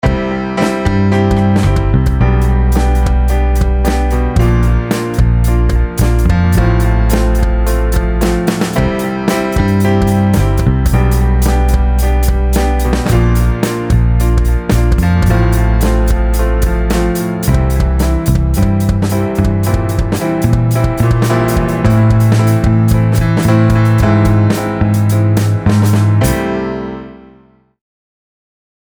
Now listen to the same drummer rushing the tempo:
Can you hear how the drummer reached the beat ahead of time?
Rhythmic-Accuracy-2-EX2-BAD-Drummer.mp3